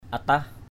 /a-tah/